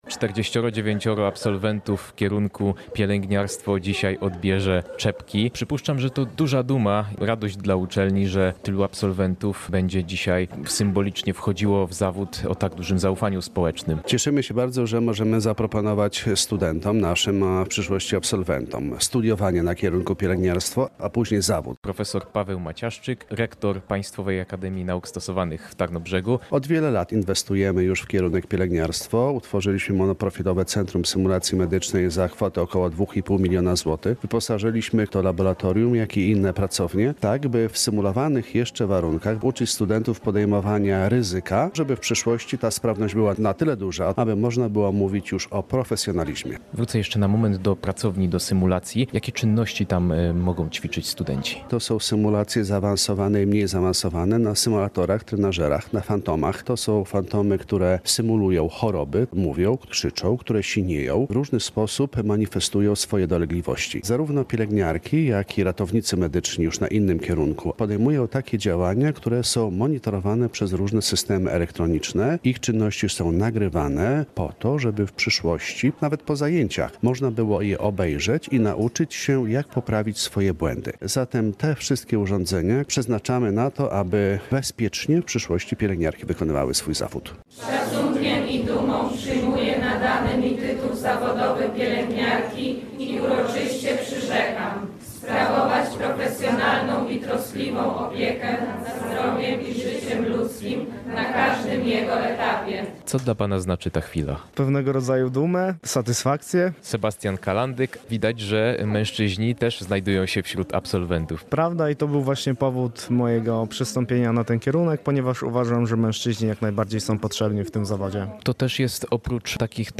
Tekst i relacja